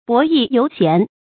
博弈猶賢 注音： ㄅㄛˊ ㄧˋ ㄧㄡˊ ㄒㄧㄢˊ 讀音讀法： 意思解釋： 后指不要飽食終日無所事事。